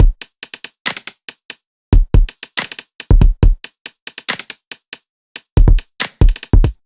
Index of /99Sounds Music Loops/Drum Loops/Hip-Hop